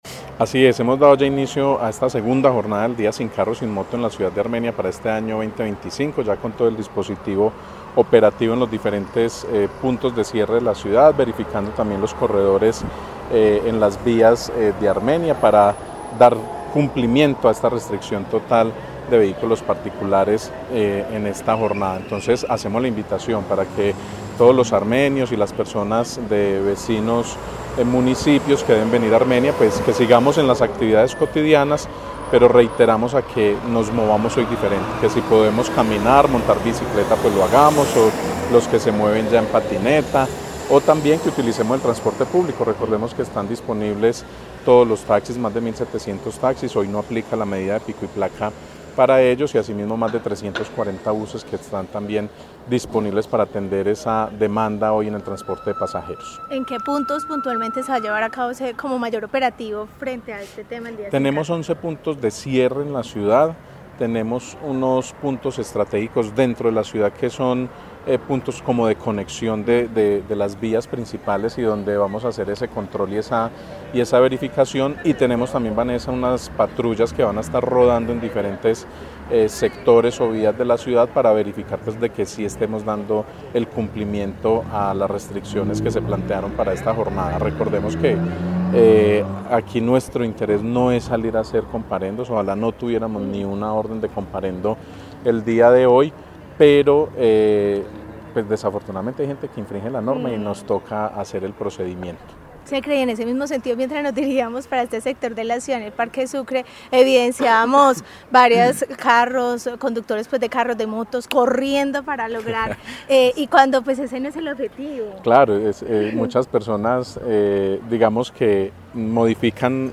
Secretario de Tránsito de Armenia